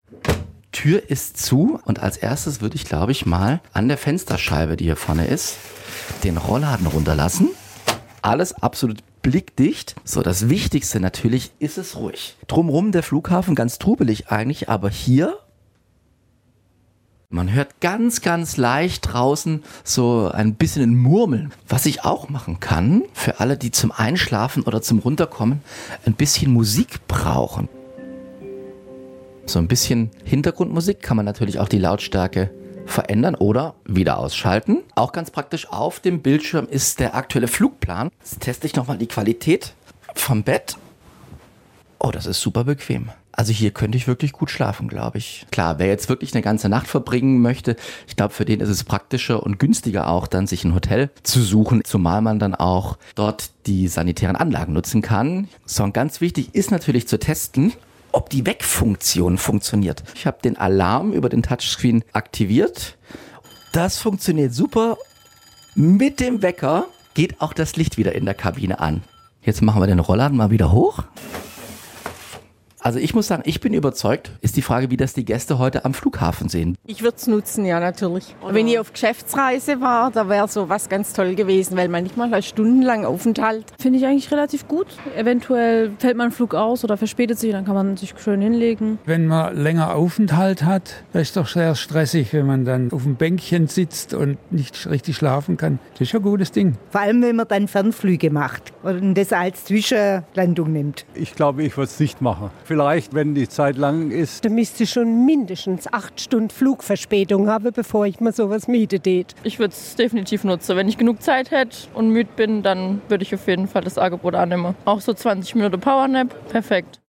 Auf Nachfrage äußern sich einige Reisende positiv über die neue Schlafkabine: Für Geschäftsreisende, das Personal von Fluggesellschaften und bei längeren Aufenthalten am Flughafen habe das Vorteile.